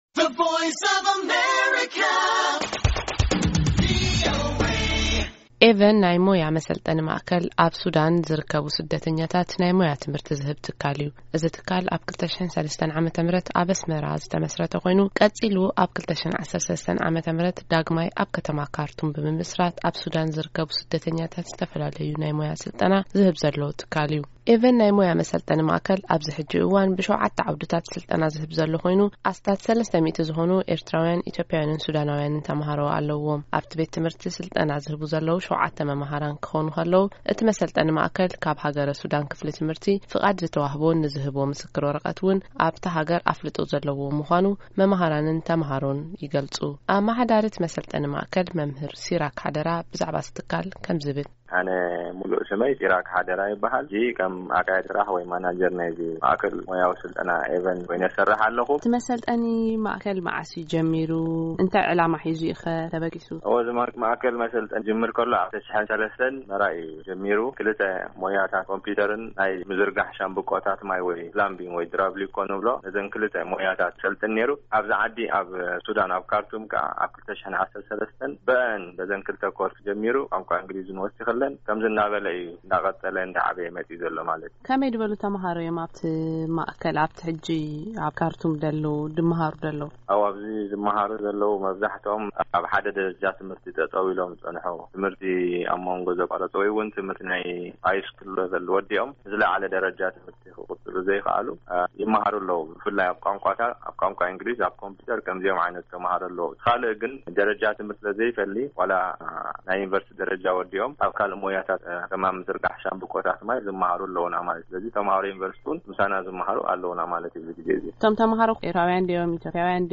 ምስ መማሃራንን ተማሃሮን እቲ መሰልጠኒ ማእከል ዝተካየደ ቃለ መጠይቕ ኣብዚ ምስማዕ ይክኣል።